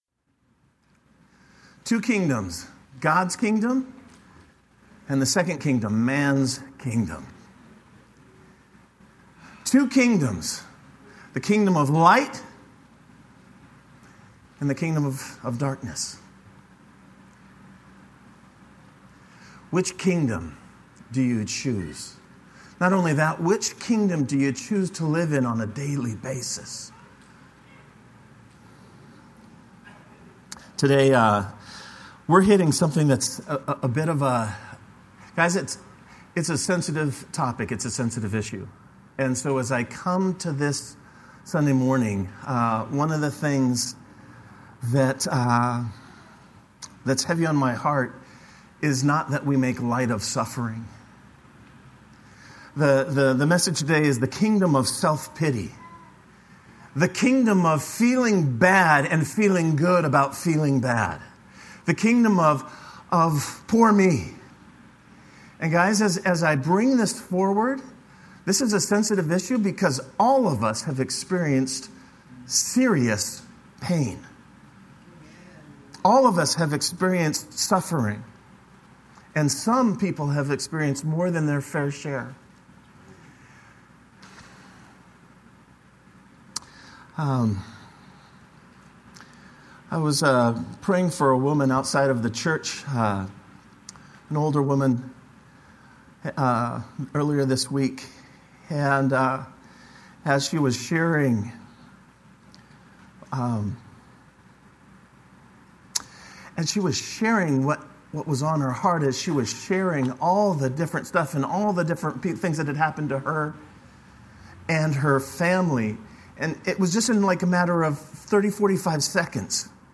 sept3-sermon.mp3